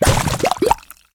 slime.ogg